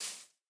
sounds / step / grass1.ogg
grass1.ogg